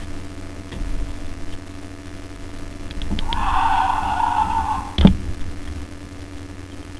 Push arm down for Ferocious Roar.
This also produces a roar, which is relatively high pitched, at least more than you might expect of a carnosaur like this.
It’s less thundering and awe-inspiring than the various Rexes’ noises, but gives this creature a bit of his own character.